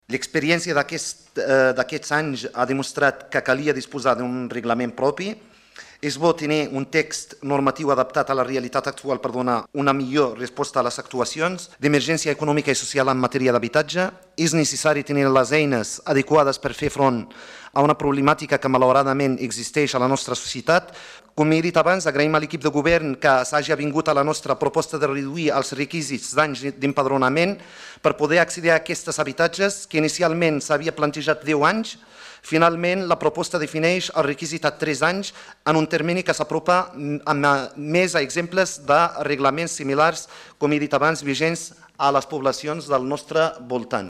Soulimane Messaoudi, portaveu ERC